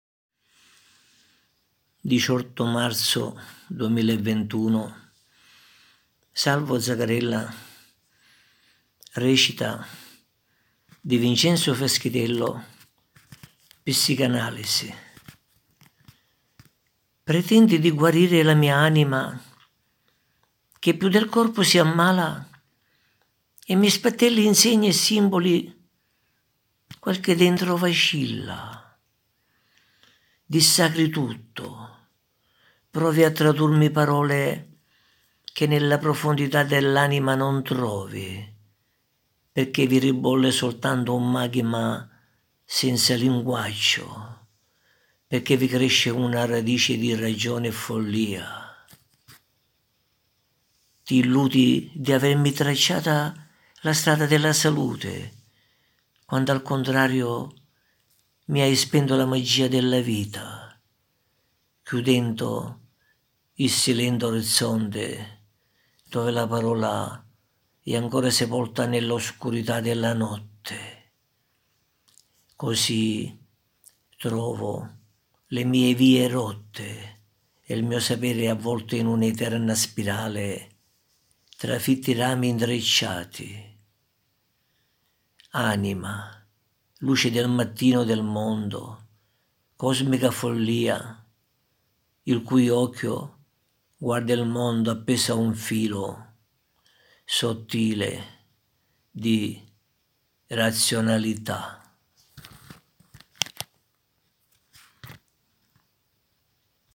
interpreta la poesia "Psicoanalisi" di Vincenzo Fiaschitello